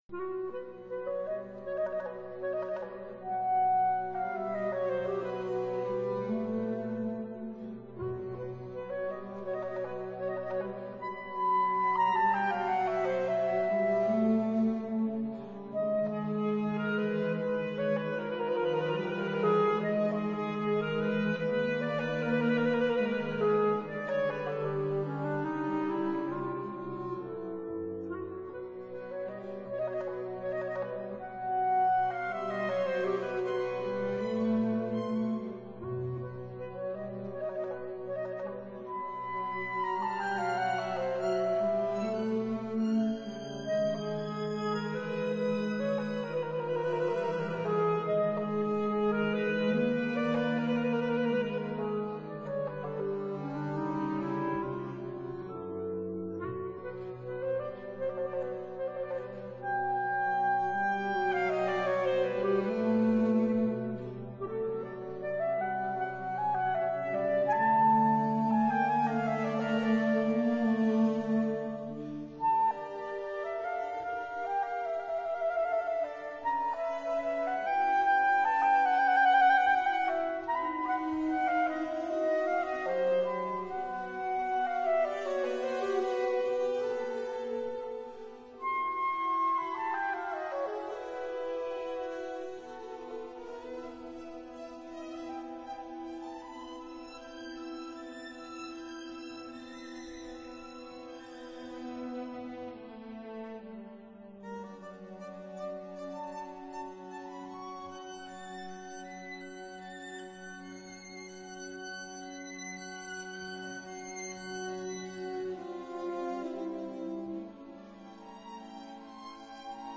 clarinet
piano